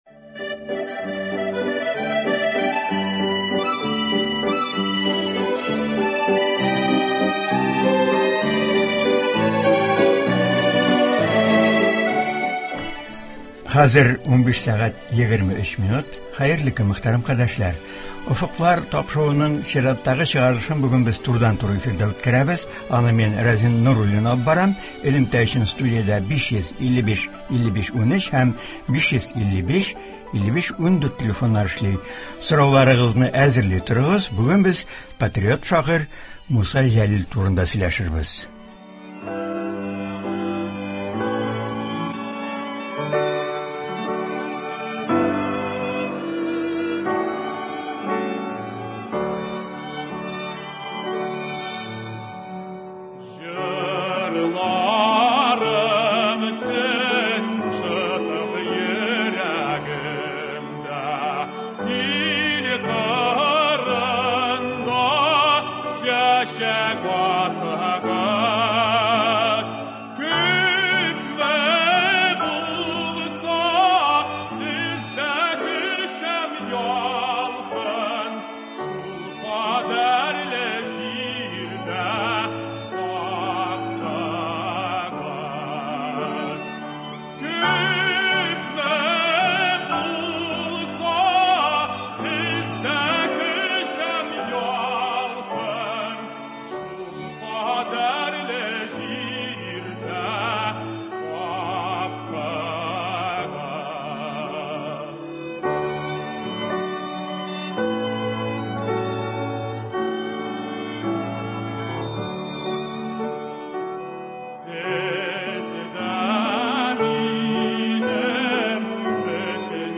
тыңлаучылар сорауларына җавап бирә.